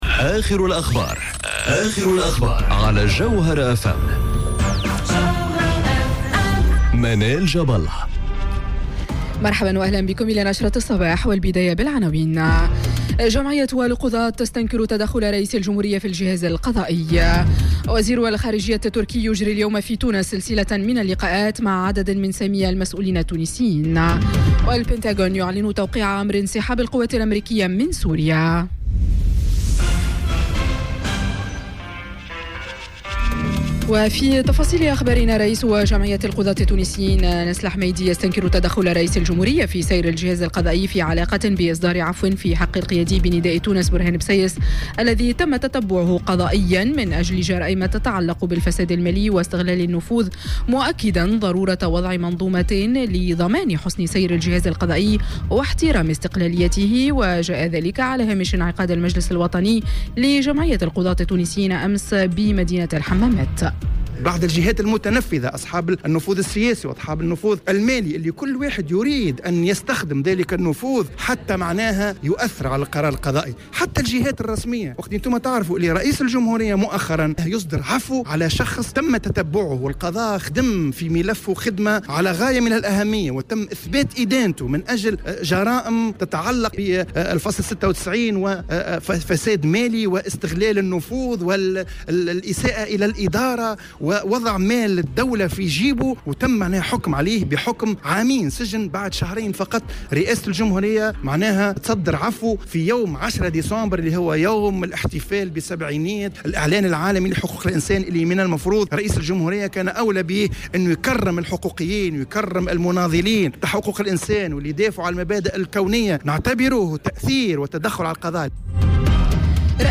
نشرة أخبار السابعة صباحا ليوم الإثنين 24 ديسمبر 2018